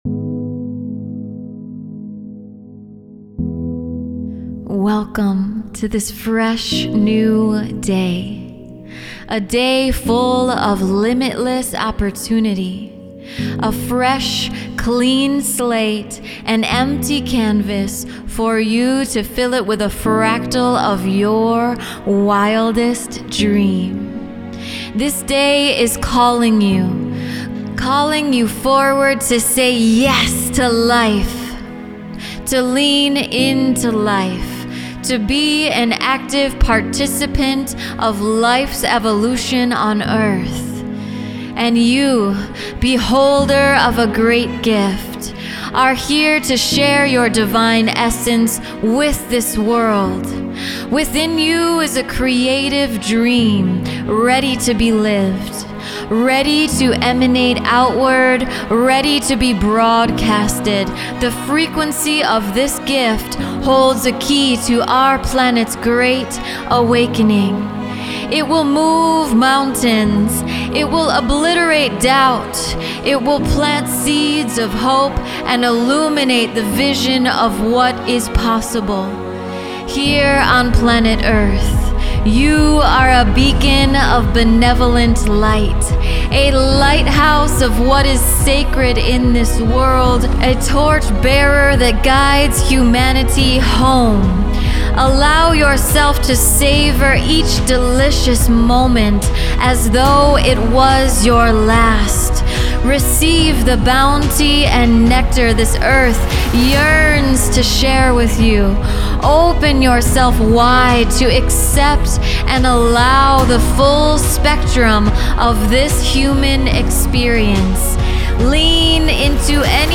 The website launch took place right at the center of this large Druid stone circle.
Tip: You can listen to this beautiful activation that accompanied the launch of the New Place website.